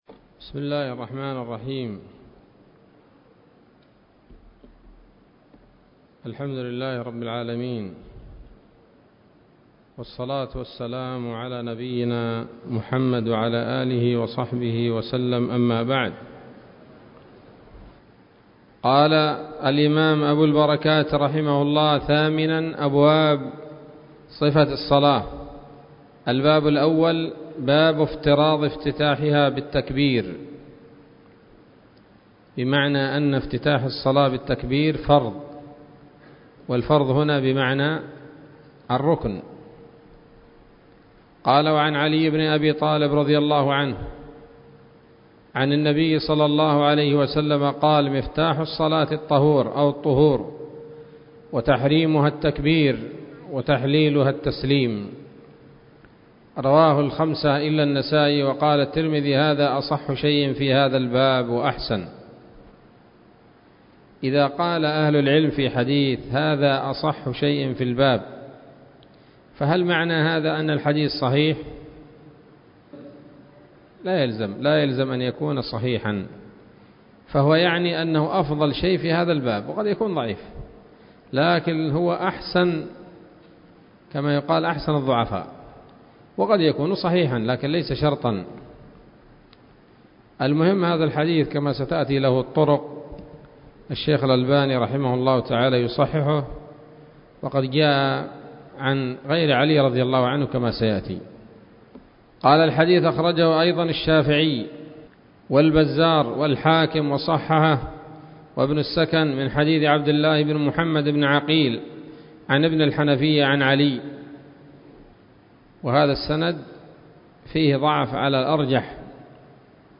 الدرس الأول من أبواب صفة الصلاة من نيل الأوطار